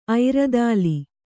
♪ airadāli